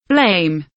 blame kelimesinin anlamı, resimli anlatımı ve sesli okunuşu